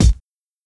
Dilla Kick 3.wav